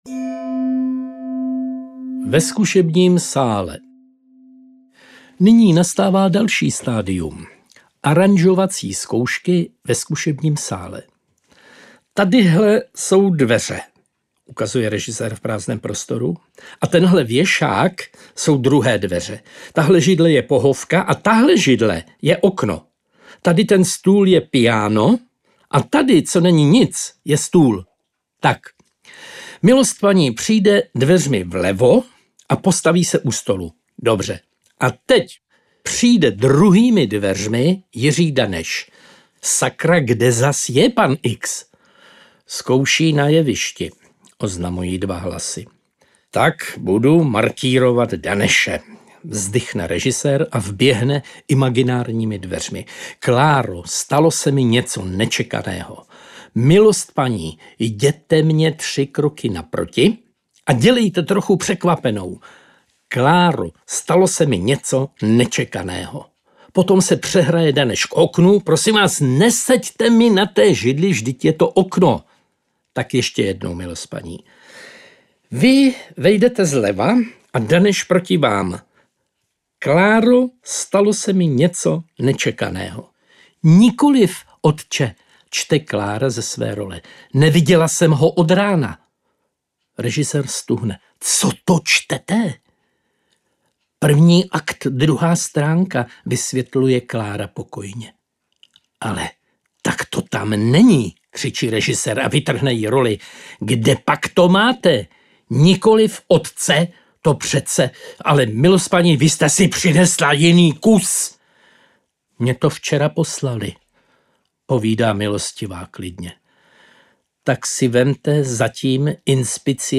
Audio knihaJak vzniká divadelní hra
Ukázka z knihy